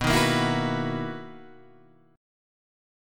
BM11 chord {7 6 8 9 7 7} chord